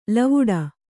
♪ lavuḍa